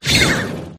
raboot_ambient.ogg